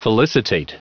Prononciation du mot felicitate en anglais (fichier audio)
Prononciation du mot : felicitate